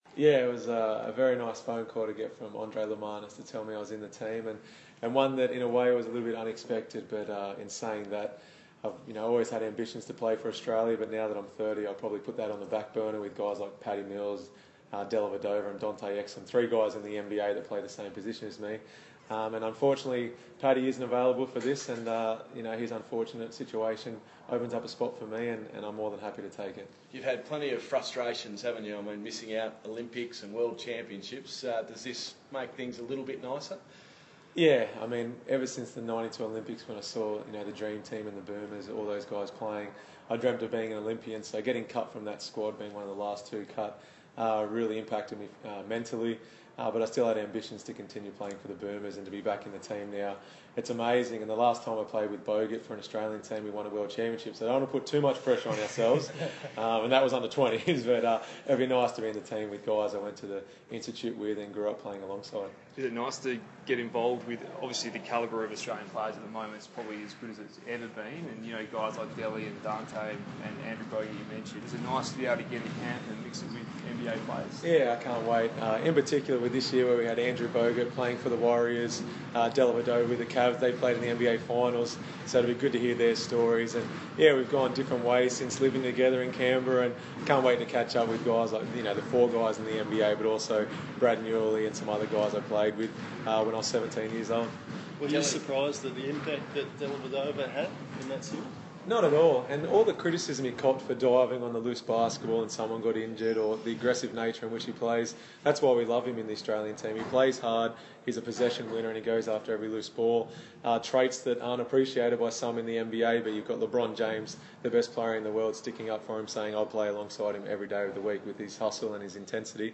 Damian Martin press conference - 14 July 2015
Perth Wildcats captain Damian Martin speaks to the media after being named in the Australian Boomers team for the 2015 Oceania Championships.